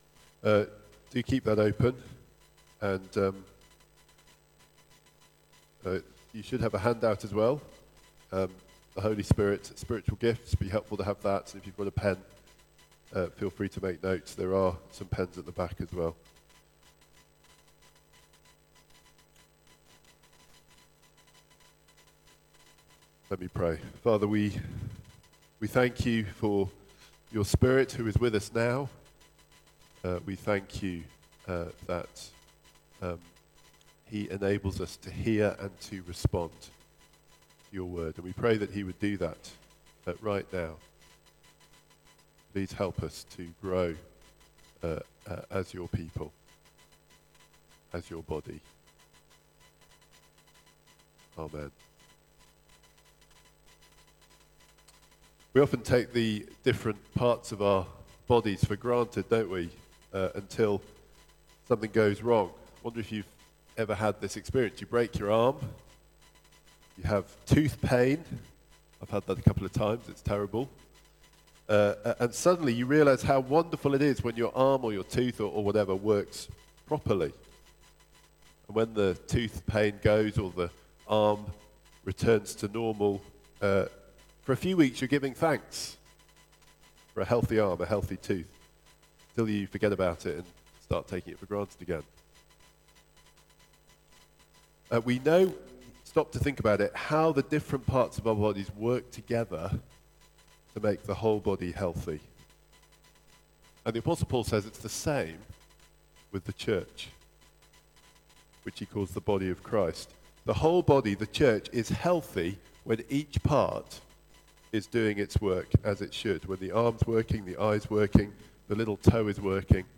Media Library The Sunday Sermons are generally recorded each week at St Mark's Community Church.
Series: The Holy Spirit Theme: The Holy Spirit: Spiritual gifts Sermon